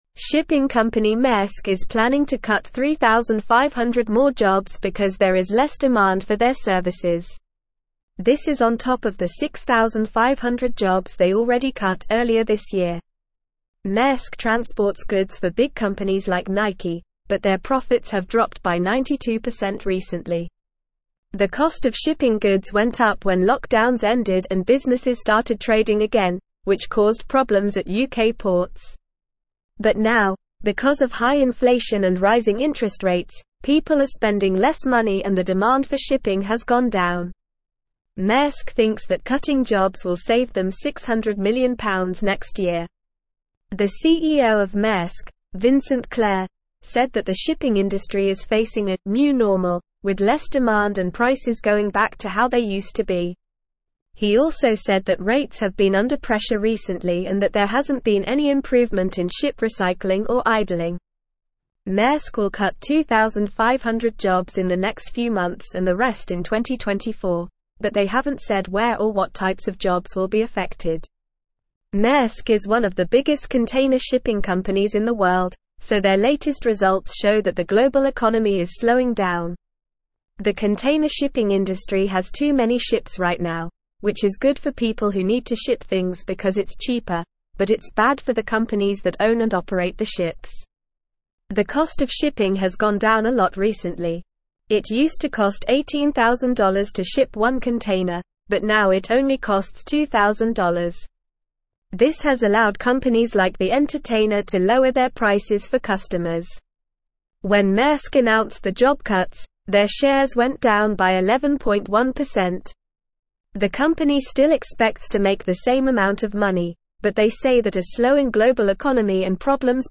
Normal
English-Newsroom-Upper-Intermediate-NORMAL-Reading-Maersk-to-Cut-3500-Jobs-Amid-Lower-Demand.mp3